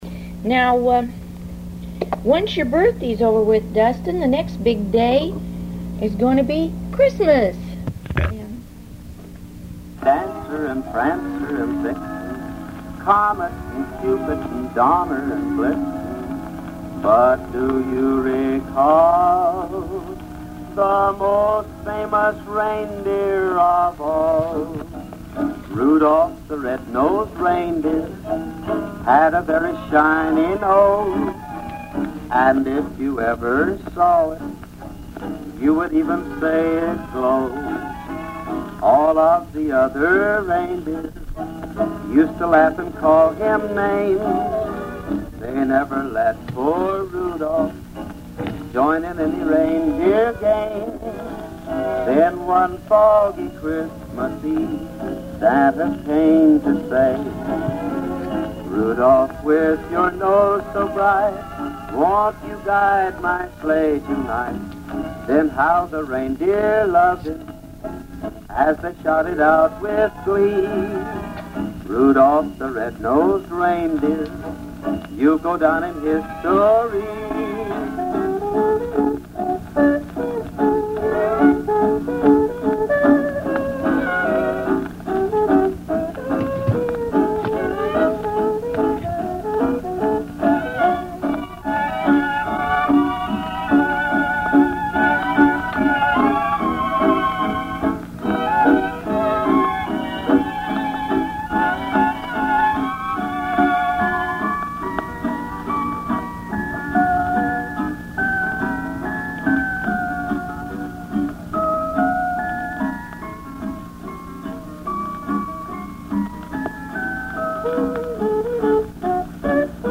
Christmas Music